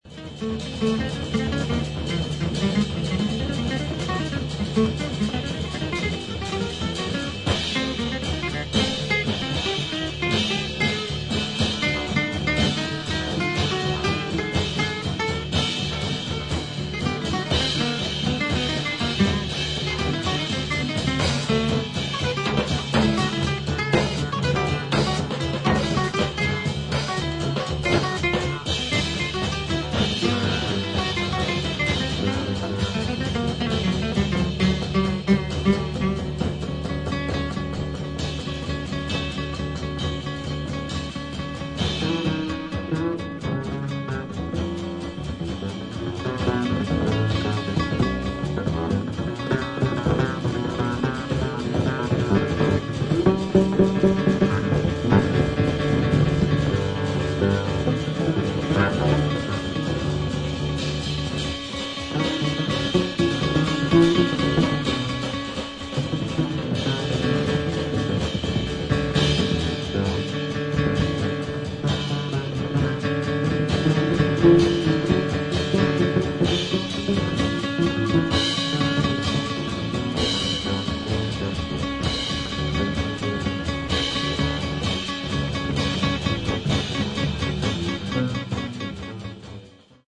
アメリカン・ルーツをベースにしながらも、中東的なフレーズが印象的な楽曲など、自由度の高いセッション・フリージャズ傑作。